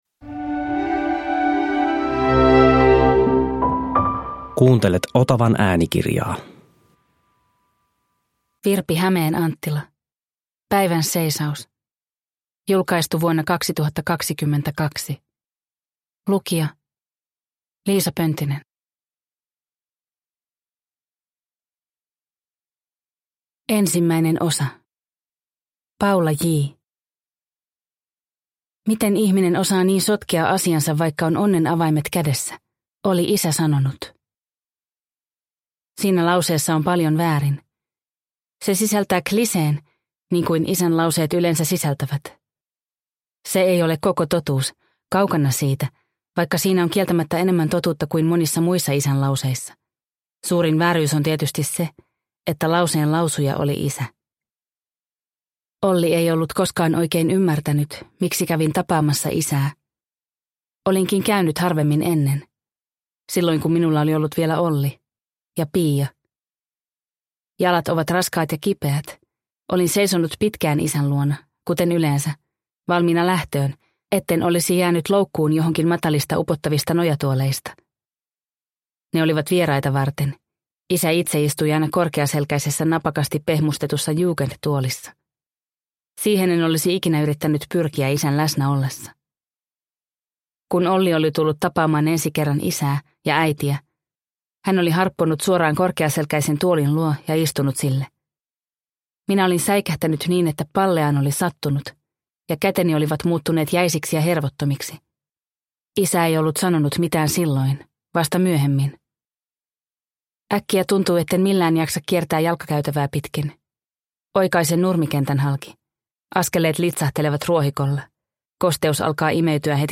Päivänseisaus – Ljudbok – Laddas ner